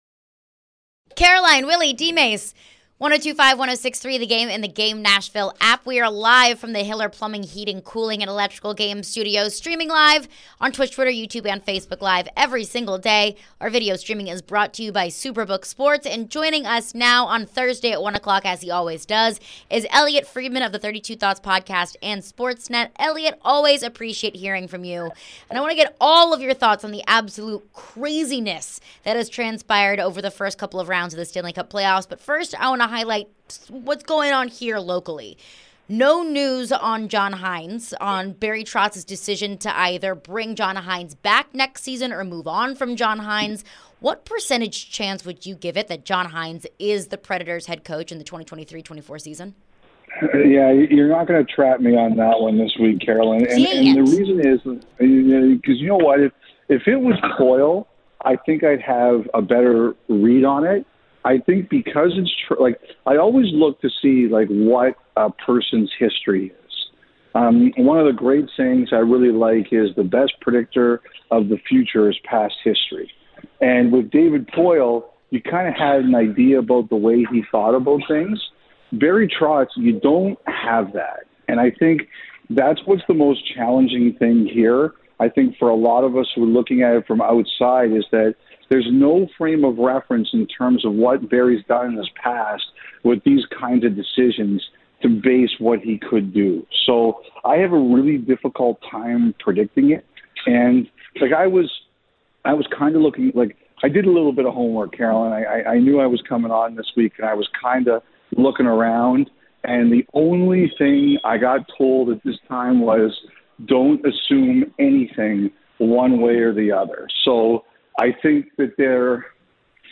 Elliotte Friedman Interview (5-4-23)
Sportsnet's Elliotte Friedman joined the program for his weekly visit. He highlighted the latest in the NHL & more.